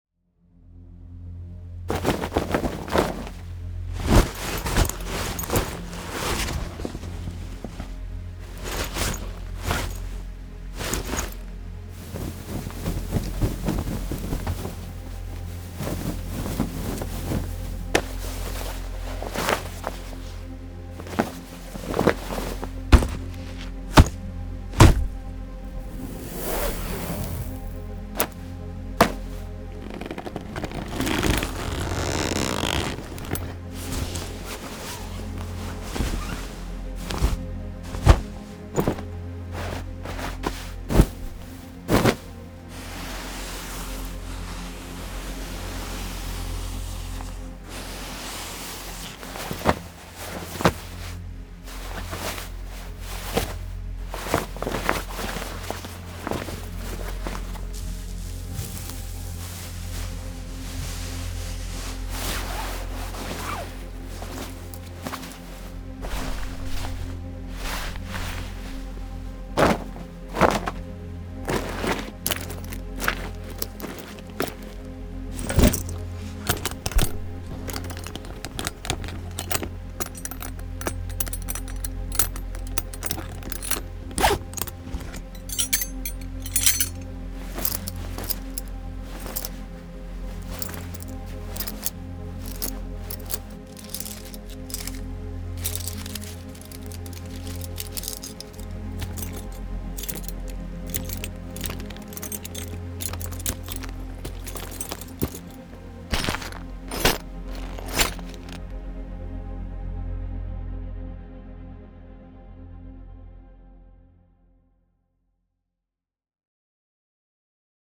微かな衣服の擦れる音から軽いギア操作音まで、すべてのサウンドはキャラクターの動作に存在感、細部、そして個性を加えるよう設計されています。
95のキャラクター移動サウンド
Cloth Moves（35） – ジャケット、布の動き、袖の摩擦、胴体の動き
Gear Movements（30） – 装備操作、ストラップ、ポーチ、軽装戦術ギア
Body Gestures（30） – 微細な動き、姿勢の変化、身体的反応
デモサウンドはコチラ↓